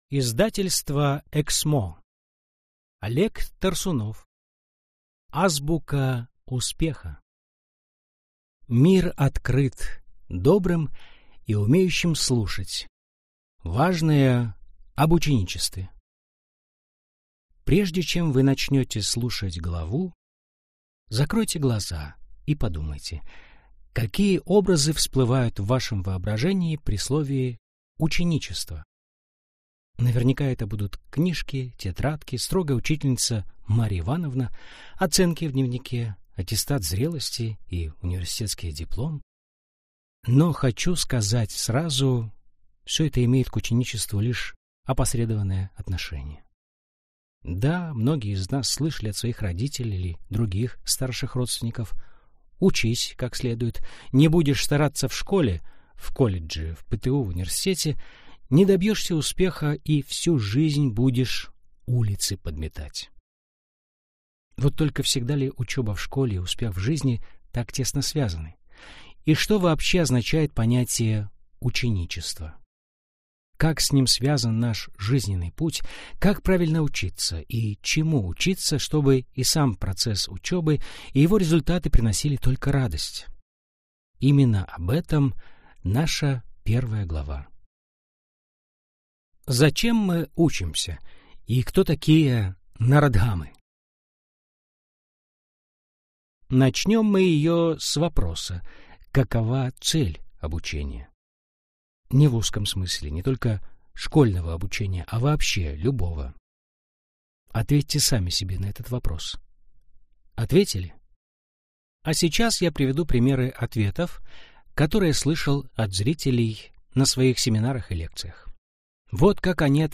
Аудиокнига Азбука успеха. Путь к процветанию без преград и сомнений | Библиотека аудиокниг